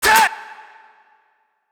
Vox (Plugg)(1).wav